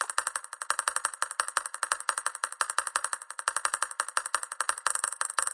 鼓声 " 平帽
描述：这个很酷的踩镲循环是由乒乓球弹跳的噪音组成的。
Tag: 踩镲 乒乓球